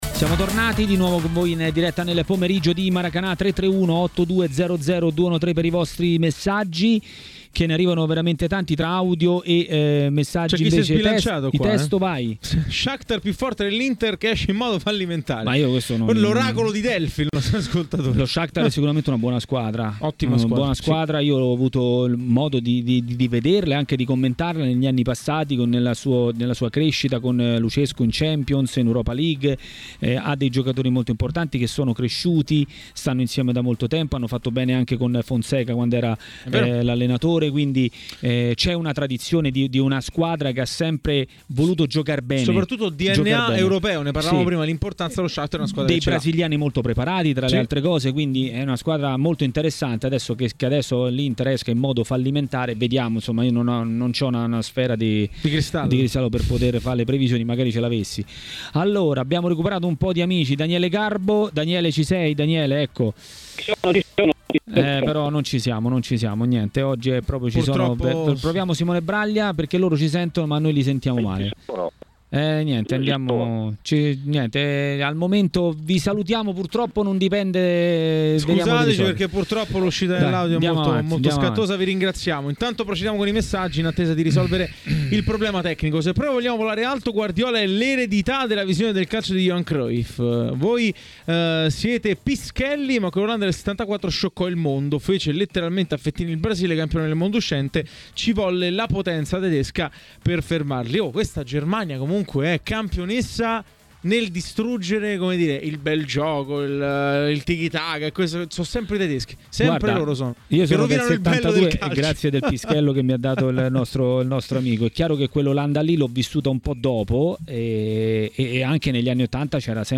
A Maracanà, nel pomeriggio di TMW Radio, l'ex calciatore e commentatore tv Massimo Paganin ha parlato di Inter e non solo.